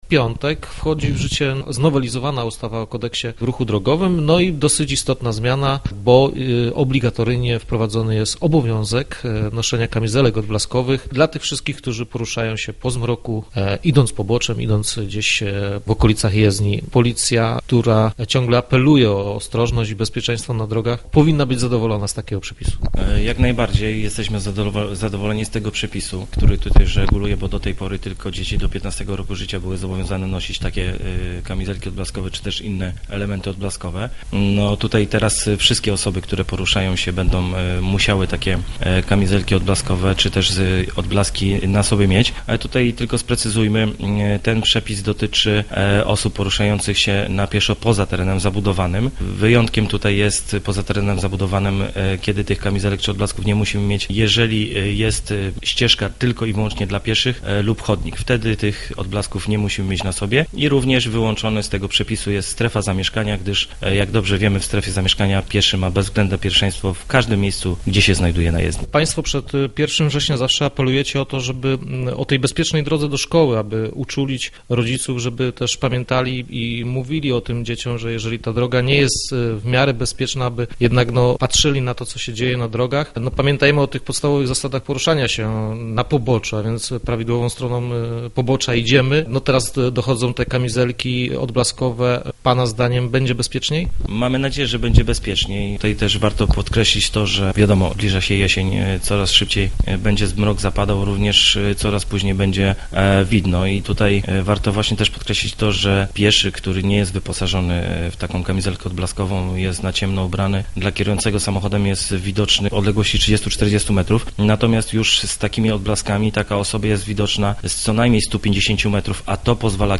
Rozmowy Elki